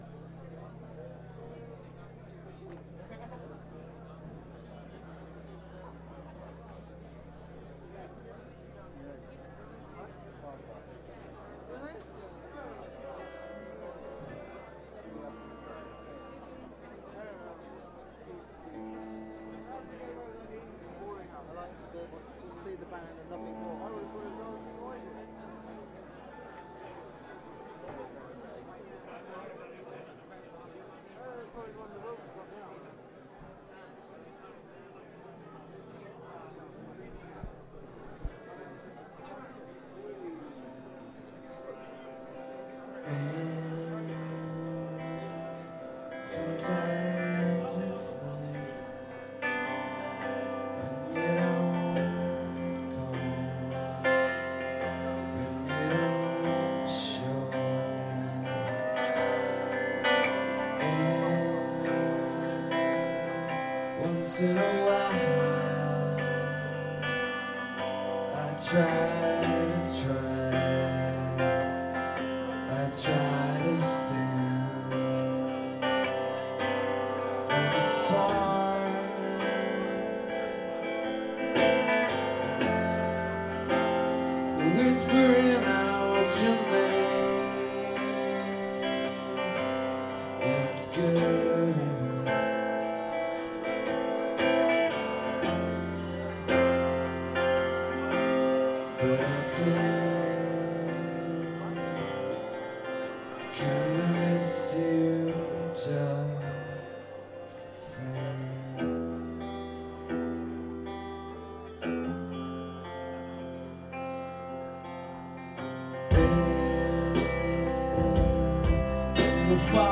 highbury garage london june 29 2000